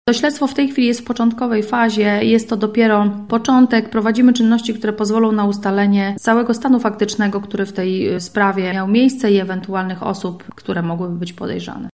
-mówi